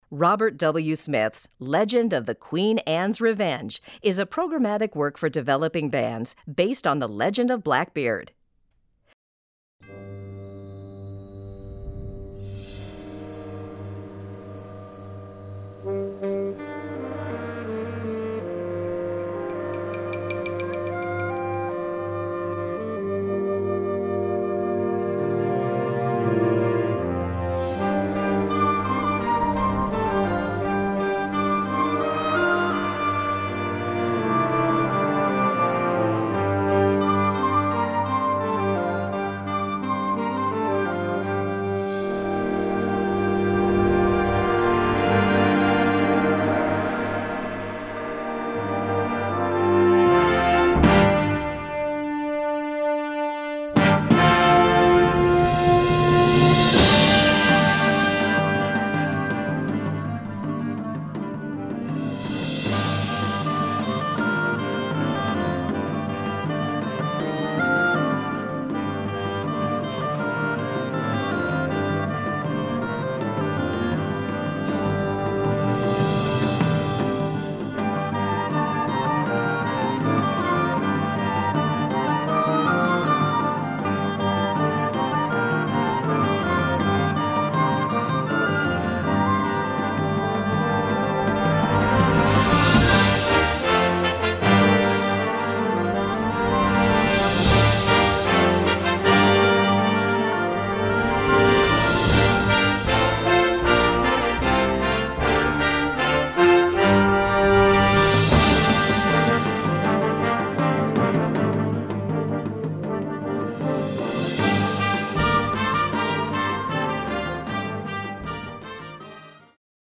Category Concert/wind/brass band
Instrumentation Ha (concert/wind band); YB (young band)